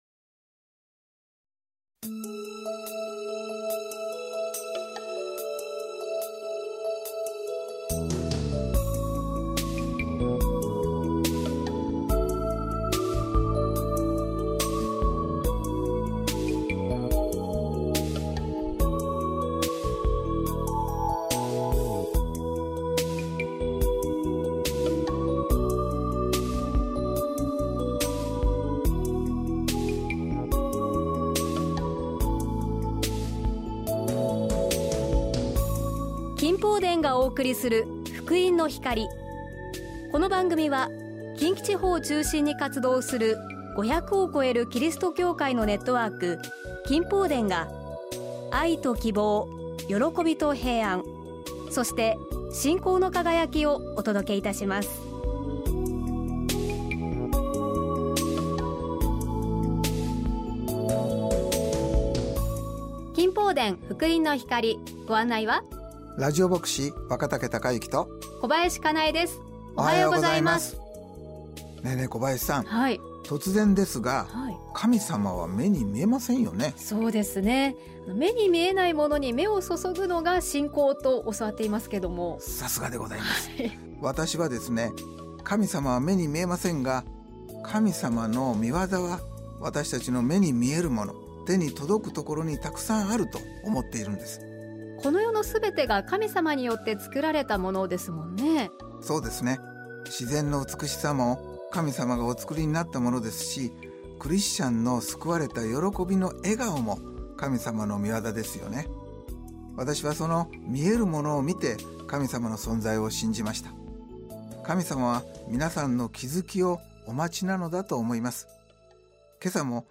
信仰体験談